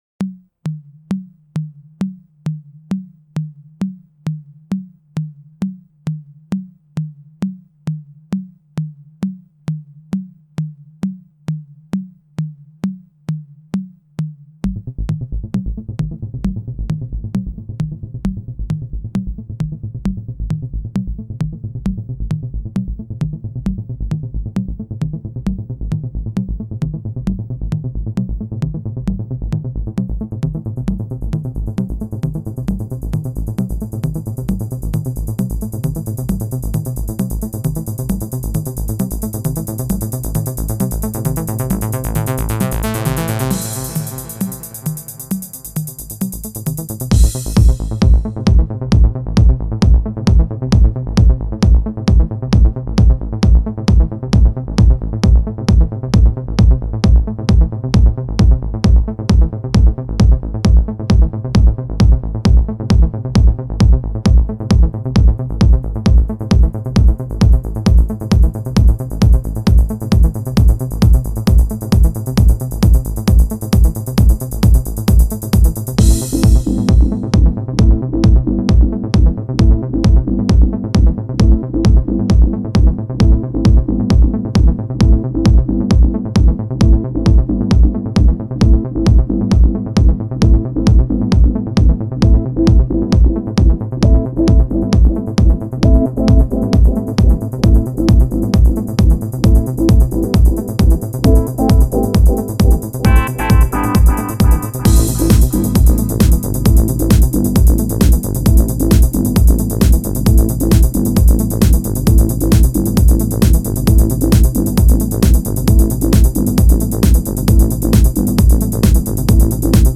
finest electronic music.